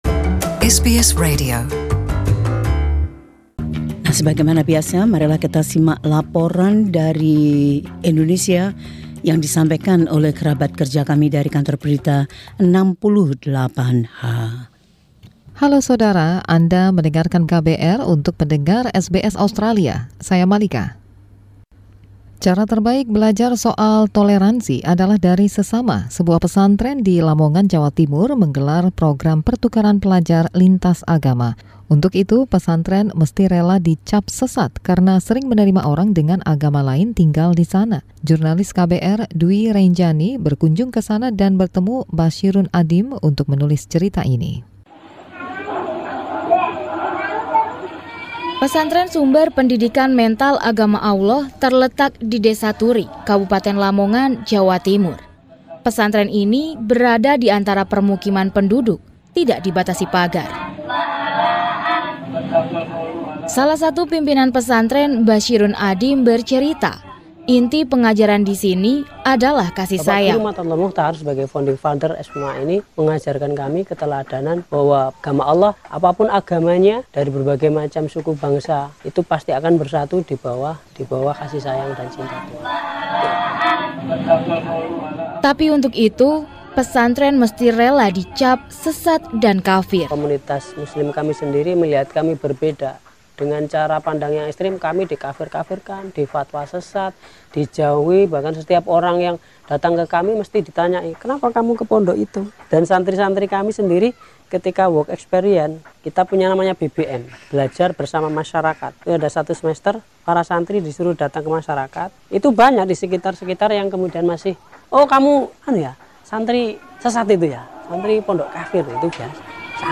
Laporan KBR 68H Report: Mengajarkan kasih sayang.